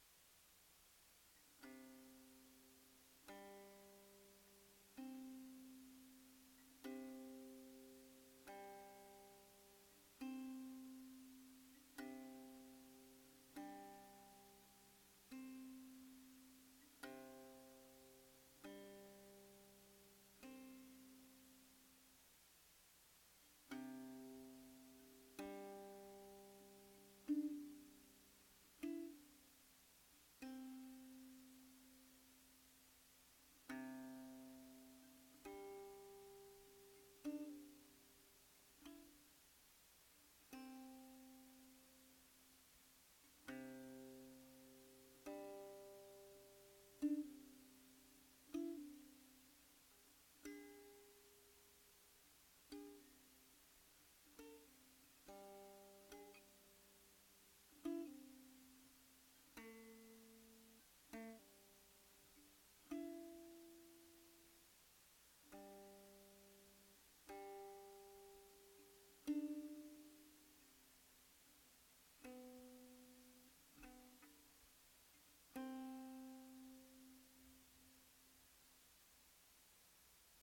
三味線
天候：強風の晴れ 駒：象牙（オリジナル）